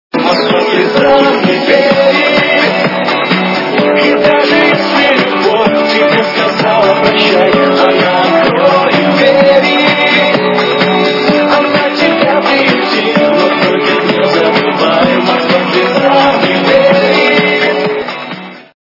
русская эстрада
rmx качество понижено и присутствуют гудки.